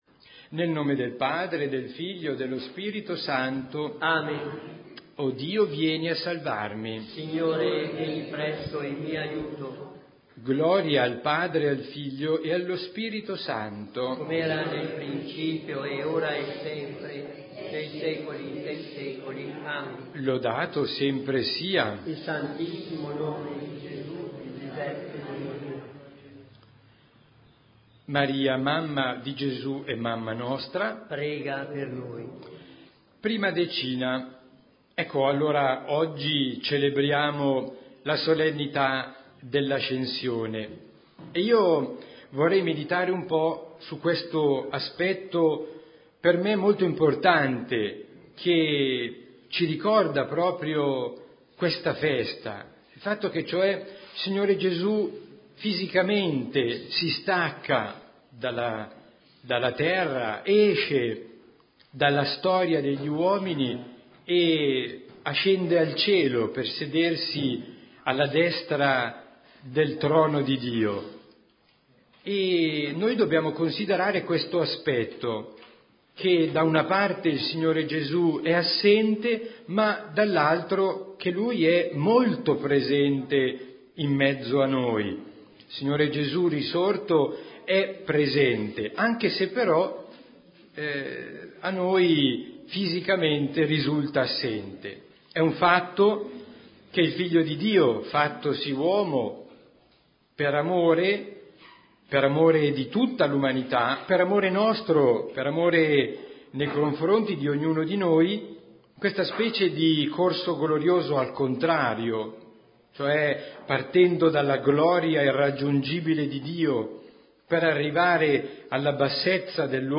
Santo Rosario